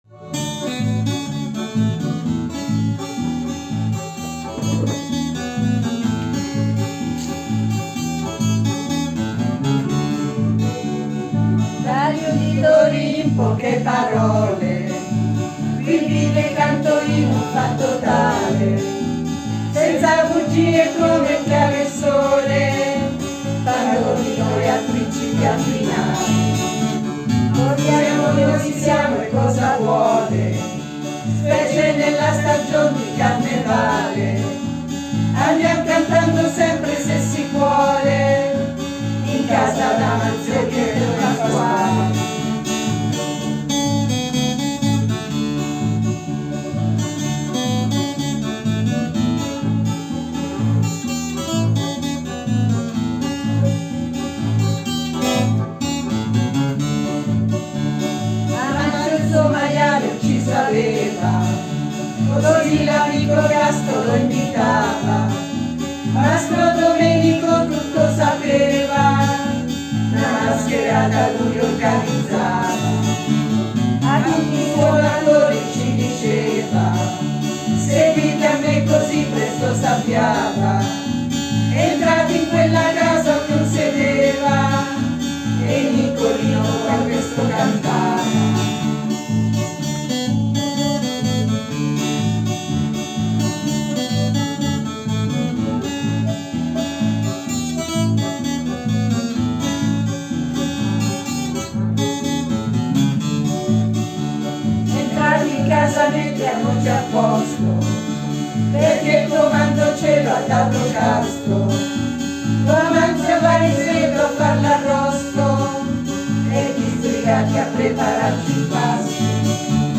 Il gruppo che canta è composto da